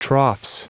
Prononciation du mot troughs en anglais (fichier audio)
Prononciation du mot : troughs